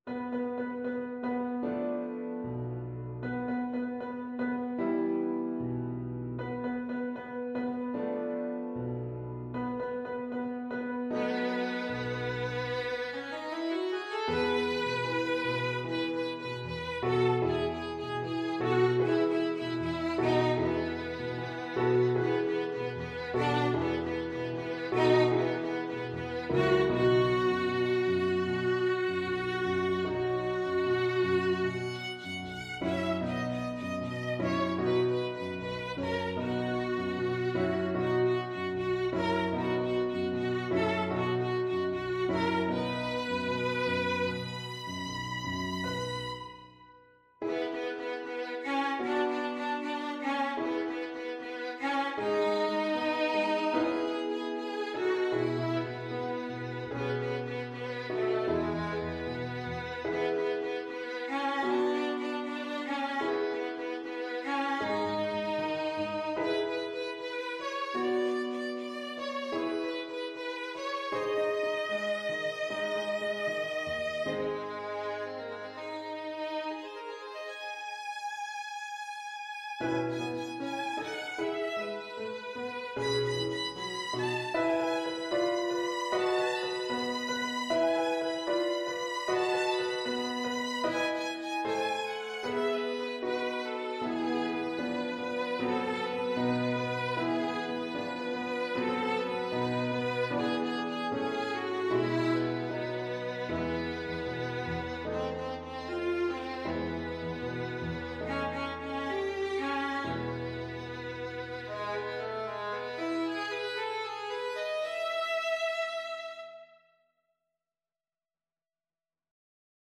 Violin
E major (Sounding Pitch) (View more E major Music for Violin )
2/4 (View more 2/4 Music)
=76 Allegretto lusinghiero =104
Classical (View more Classical Violin Music)
saint_saens_havanaise_op83_VLN.mp3